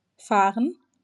wymowa:
IPA/ˈfaːrən/ /fɛːʁt/ /fuɐ/ /gəˈfaːrən/